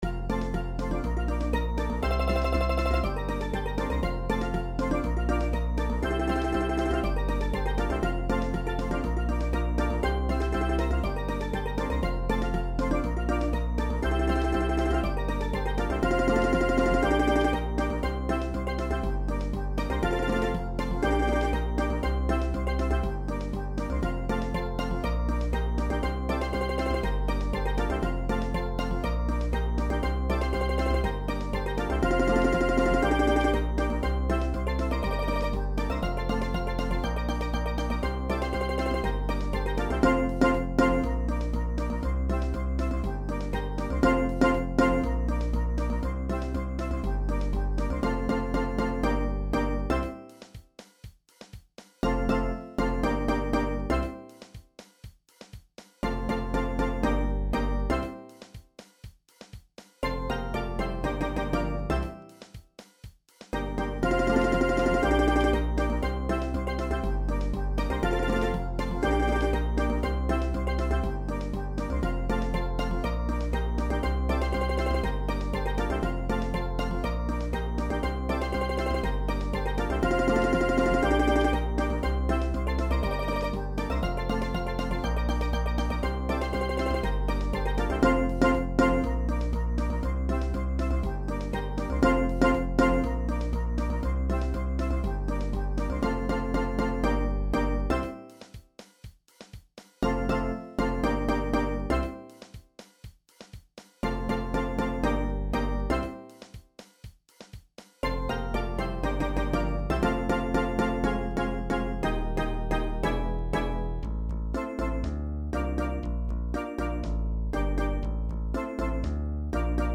Genre: Steel Drum Band